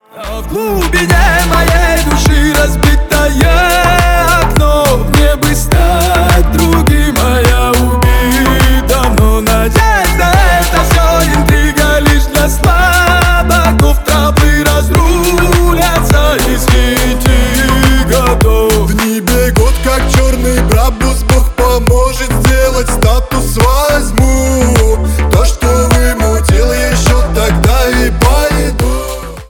громкие , поп , русские